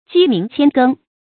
雞鳴馌耕 注音： ㄐㄧ ㄇㄧㄥˊ ㄧㄜˋ ㄍㄥ 讀音讀法： 意思解釋： 比喻婦女勤儉治家。